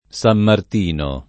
Sam mart&no] top.